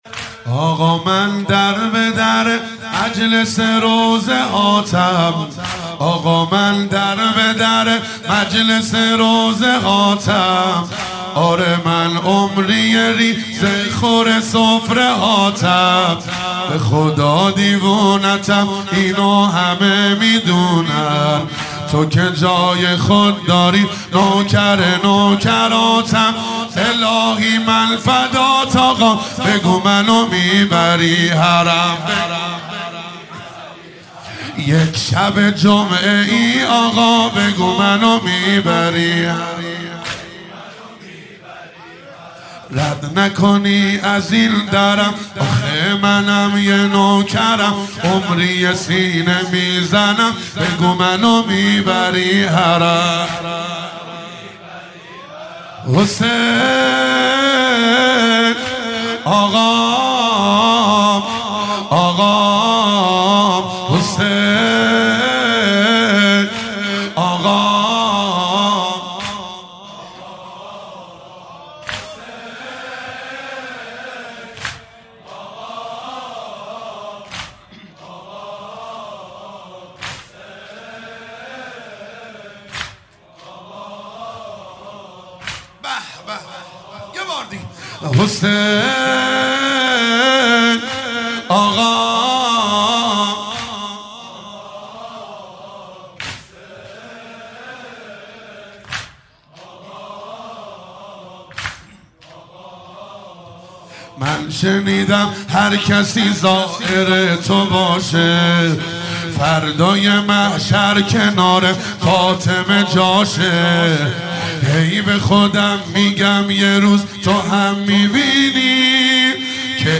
مداحی و نوحه
دانلود مداحی فاطمیه
سینه زنی در شهادت حضرت فاطمه زهرا(س)